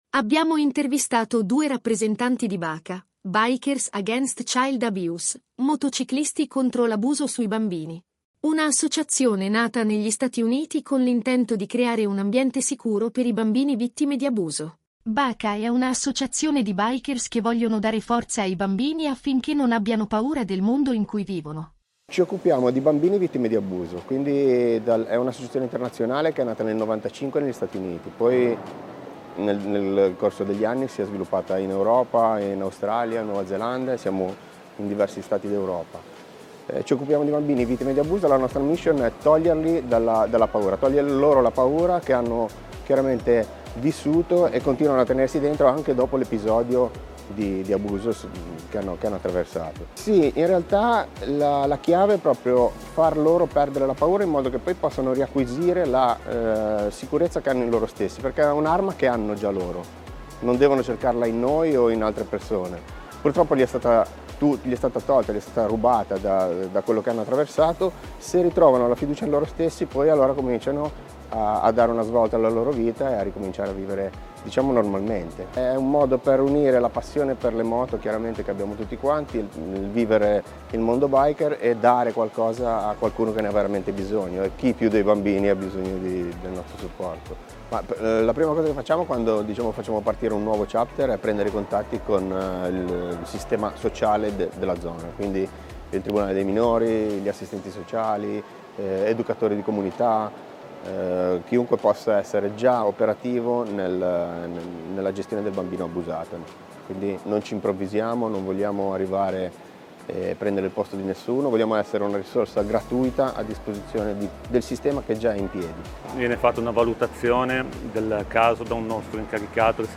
Intervista a BACA, Bikers Against Child Abuse – Consorzio Pro Loco Genova